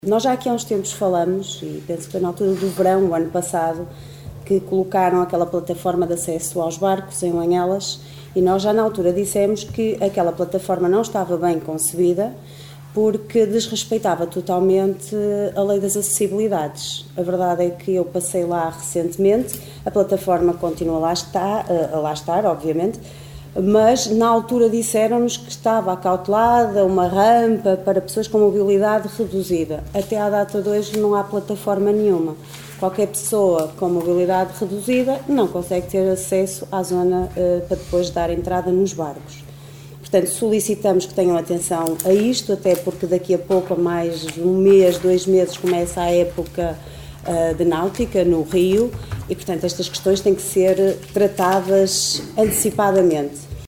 reuniao-camara-6-mar-espaco-publico-liliana-silva-001.mp3